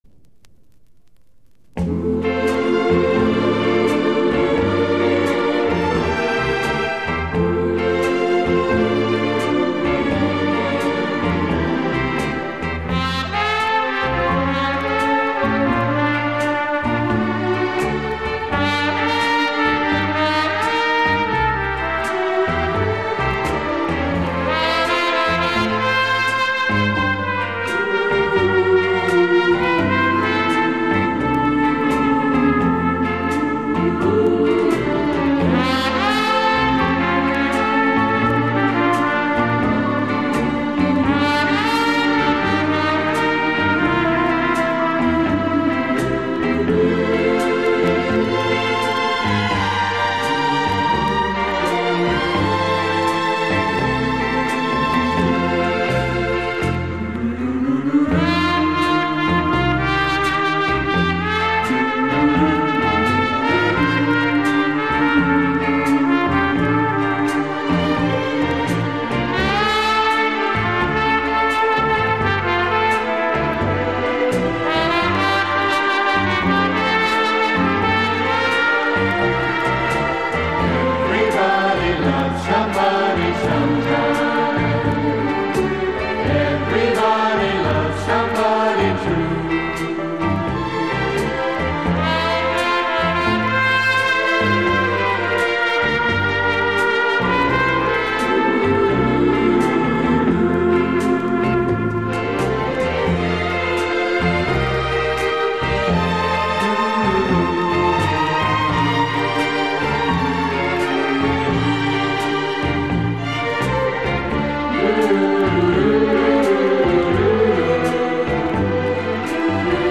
His Trumpet,Chorus & Orchestra